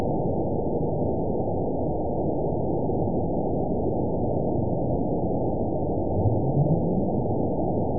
event 920331 date 03/16/24 time 23:43:06 GMT (1 year, 1 month ago) score 9.41 location TSS-AB02 detected by nrw target species NRW annotations +NRW Spectrogram: Frequency (kHz) vs. Time (s) audio not available .wav